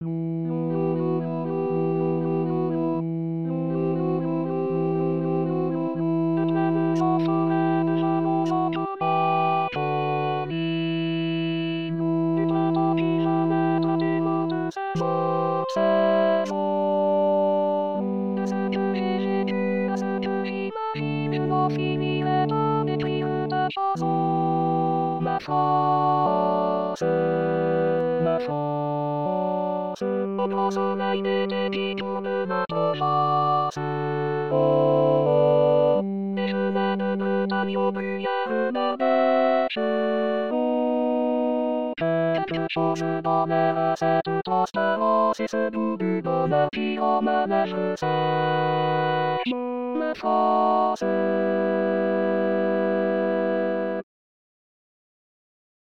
Tutti (.myr) tous les couplets